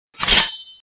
Wolverine Shing!
Category: Sound FX   Right: Personal